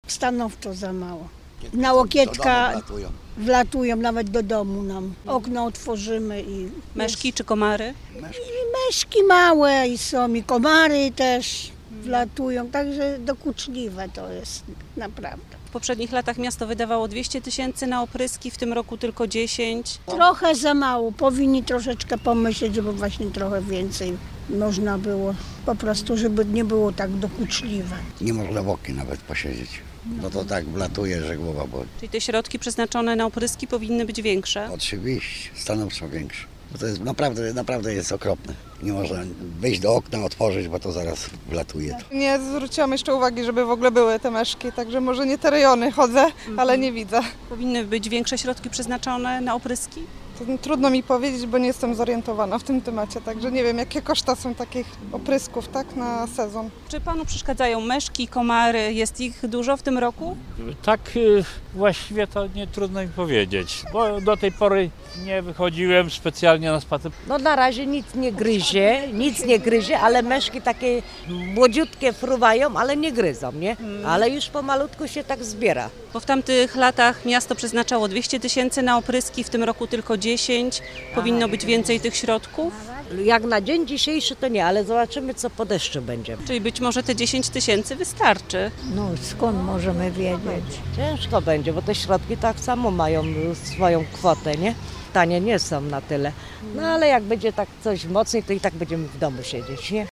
O opinie gorzowian pytaliśmy także na ulicach miasta.
sonda-o-opryskach.mp3